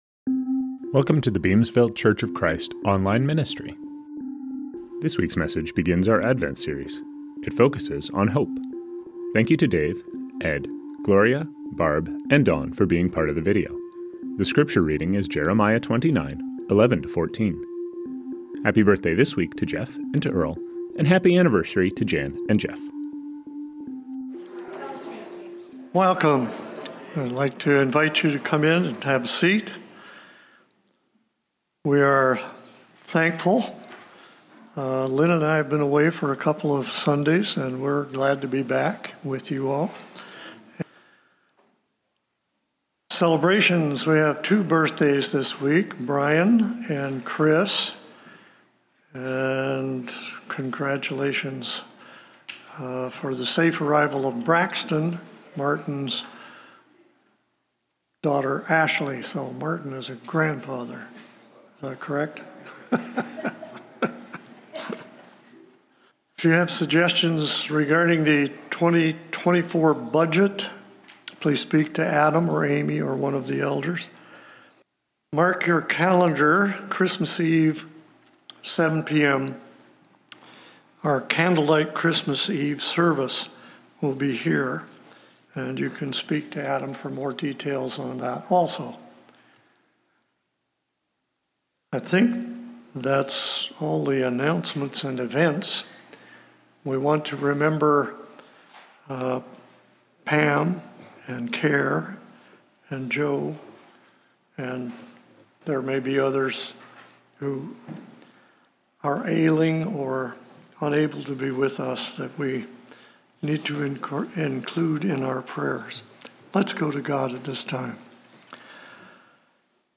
Songs from this service